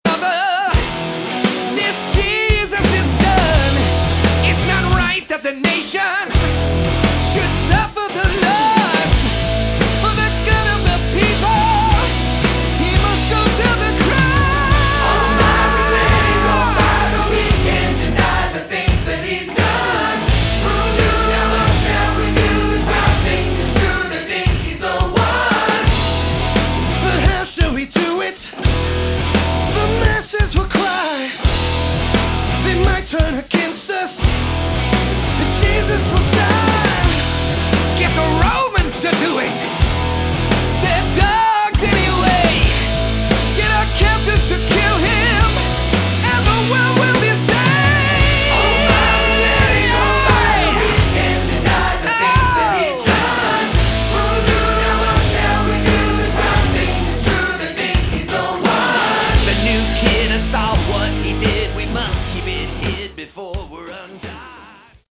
Category: Prog / AOR
keyboards, guitar
bass
drums